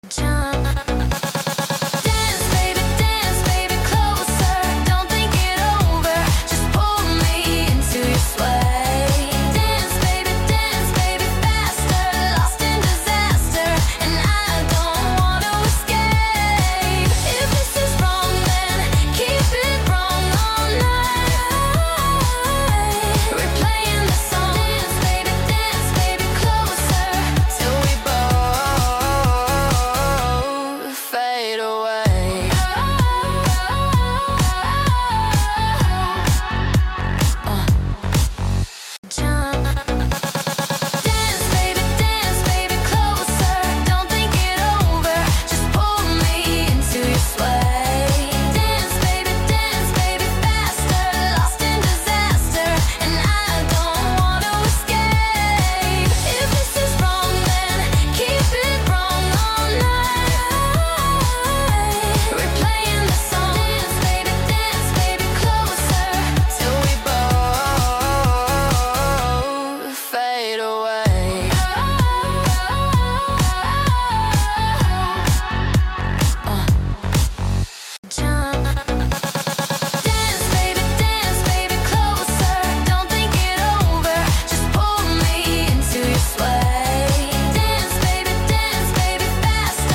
Текст песни ДИНАМИЧНАЯ МУЗЫКА